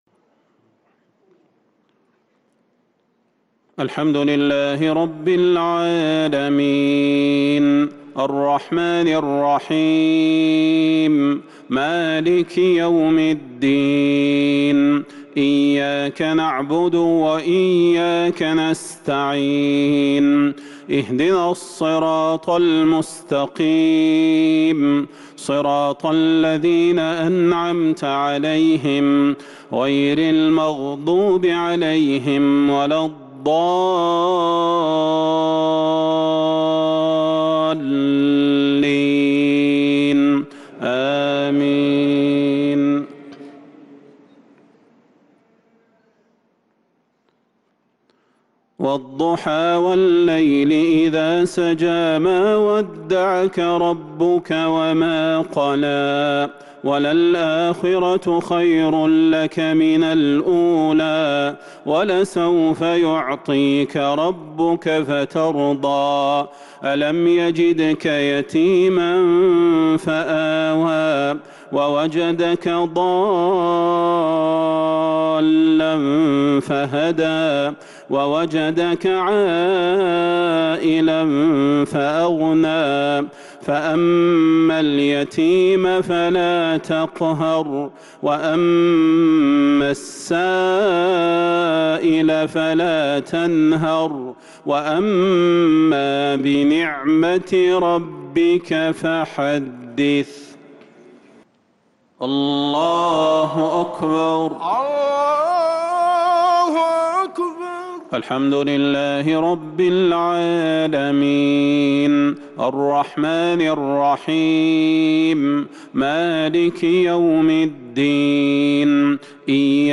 عشاء الجمعة 7-9-1443هـ سورتي الضحى و الشرح | Isha prayer from Surah Ad-Dhuha & Al-sharh 8-4-2022 > 1443 🕌 > الفروض - تلاوات الحرمين